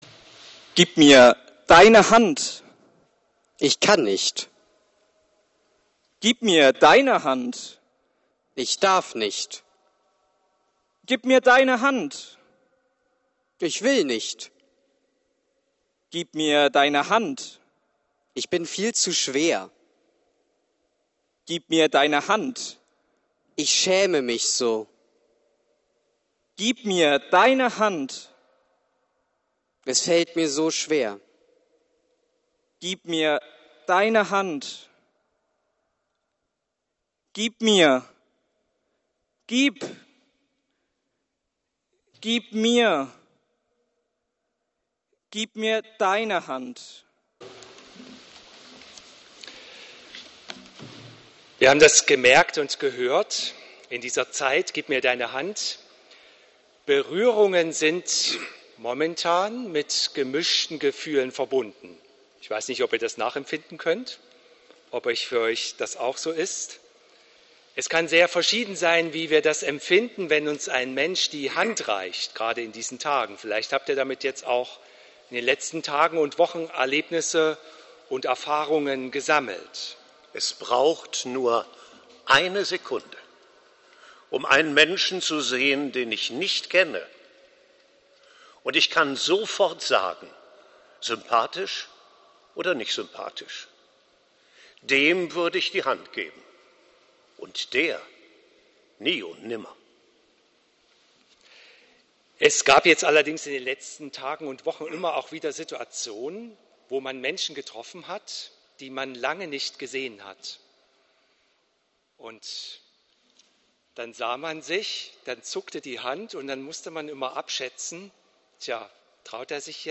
Dialogpredigt
Gehalten zur Ökumenischen Christus-Wallfahrt am 1. Mai 2022